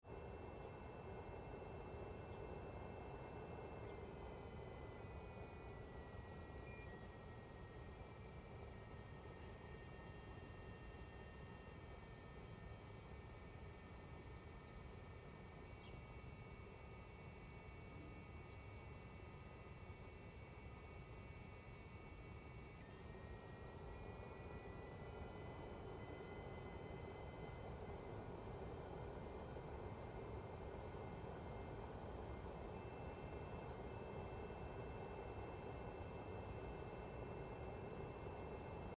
Mac Studio Owners Complain of Irritating High-Pitched Noise
My Studio (now named "Whiny") is an Ultra.